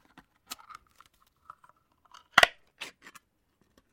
Звуки бидона
Звук щелчка крышки бидона